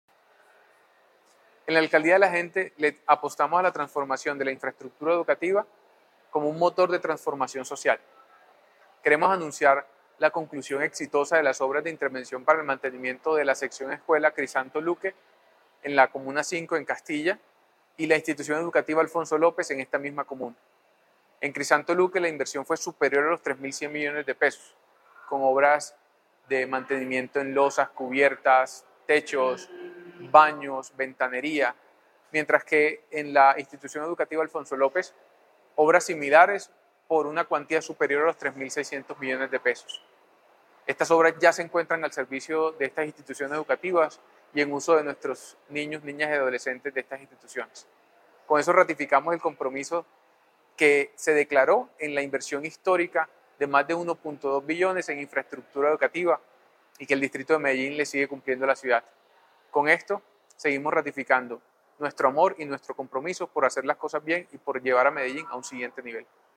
Declaraciones del subsecretario Administrativo y Financiero de la Secretaría de Educación, Anderson García Declaraciones del gerente de la Empresa de Desarrollo Urbano, Emiro Valdés López Con una inversión superior a los $42.000 millones, la Administración Distrital avanza con el plan de infraestructura educativa en la comuna 5-Castilla, donde hasta la fecha se han intervenido 26 sedes y seis más están en proceso de recibo por parte de la Secretaría de Educación.